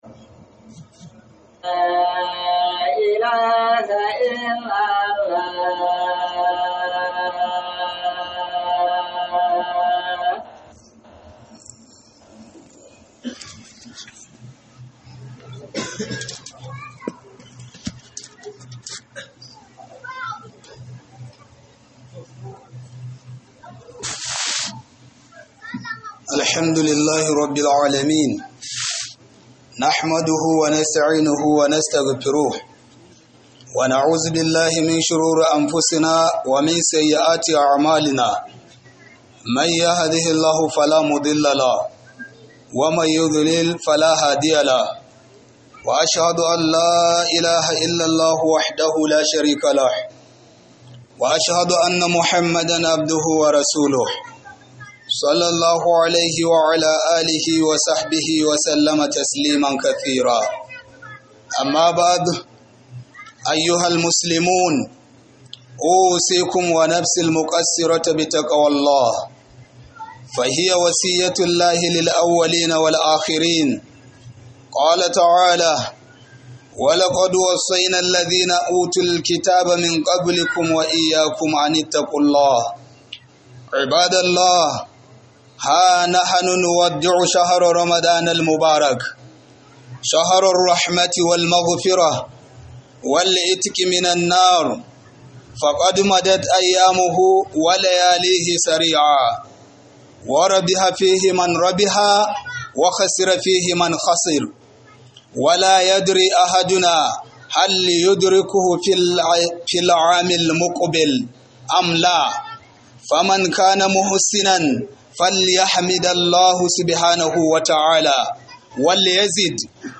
Khudubar Sallar Juma'a